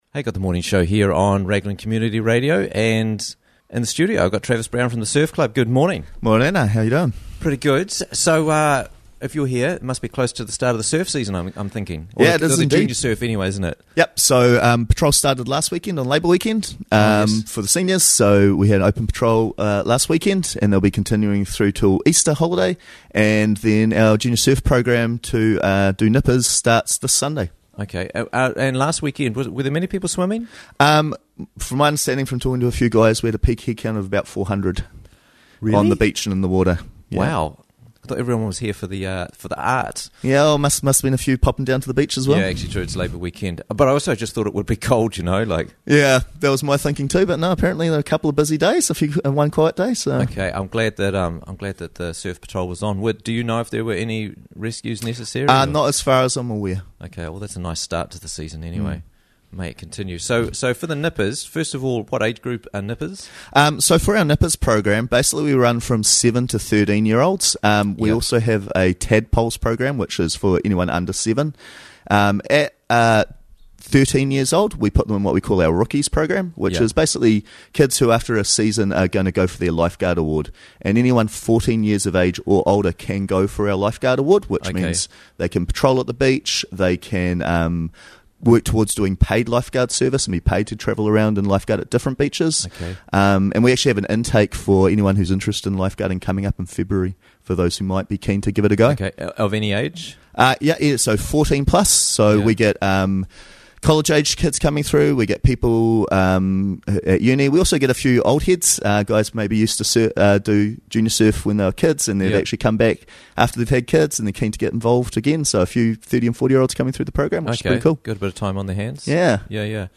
What's Happening With The Nippers This Year - Interviews from the Raglan Morning Show